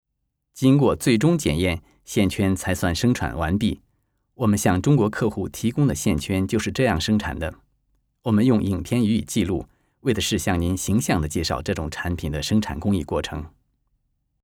» Chinesisch m.